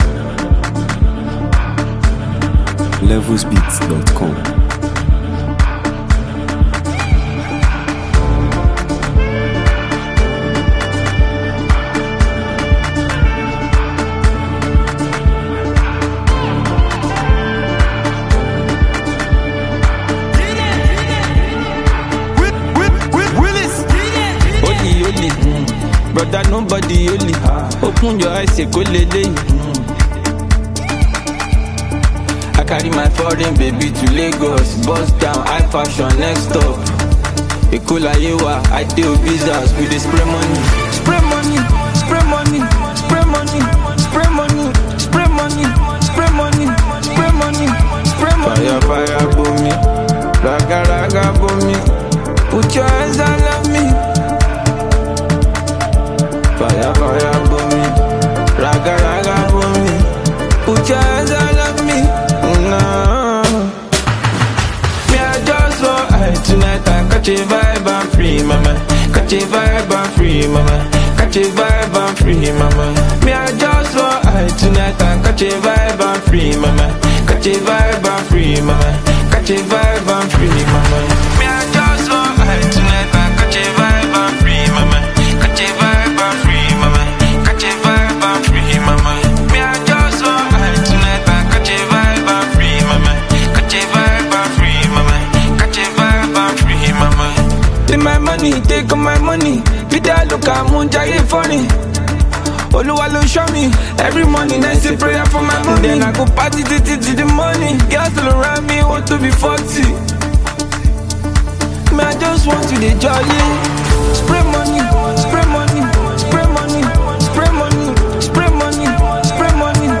If you appreciate good music with a soothing, laid-back vibe